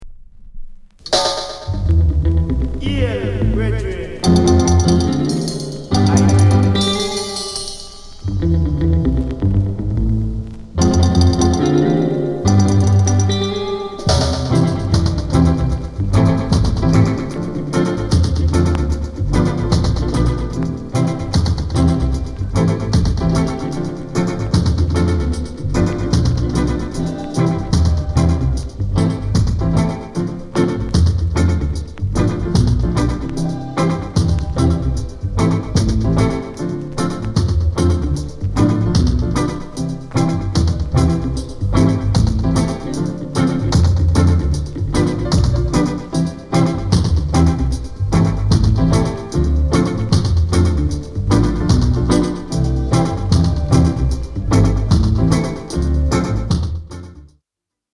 SOUND CONDITION A SIDE EX-
ROCKSTEADY